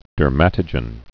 (dûr-mătə-jən)